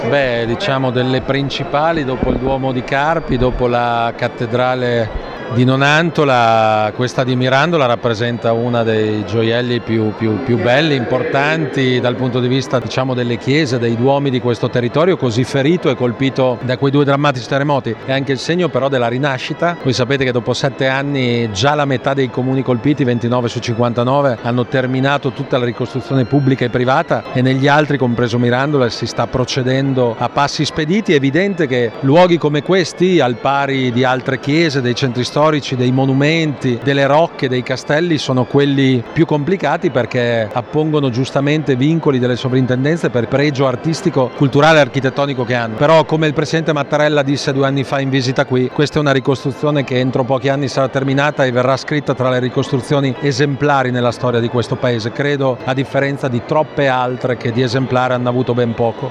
Il Presidente della Regione, Stefano Bonaccini: